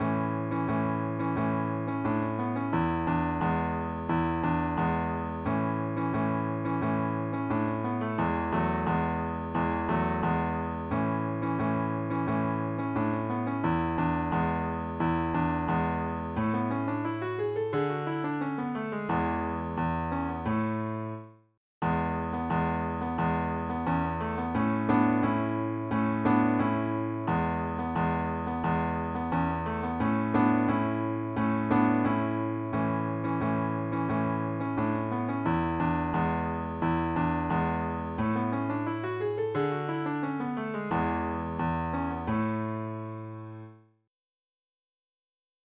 La sua musica, facile e ad effetto, è adatta a tutti.